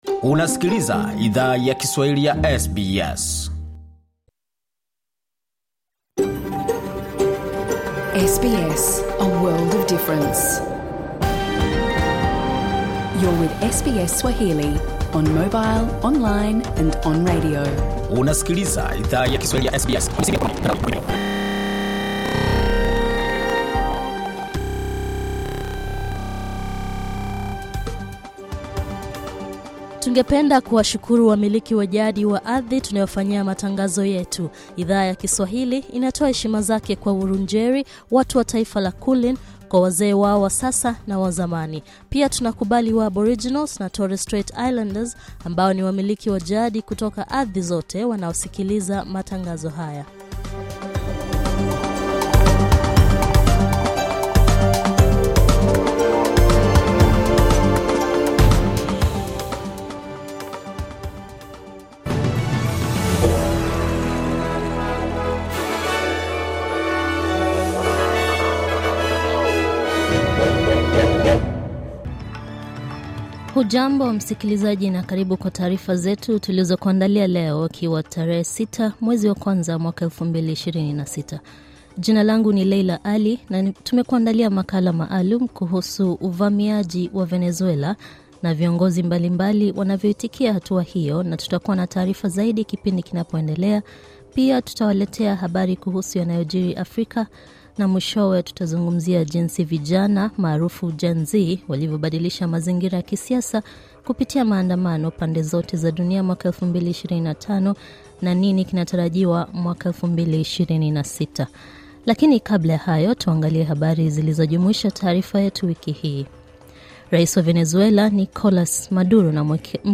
Taarifa ya habari:Maduro na mkewe wakanusha mashtaka ya ulanguzi wa dawa za kulevya na silaha jijini New York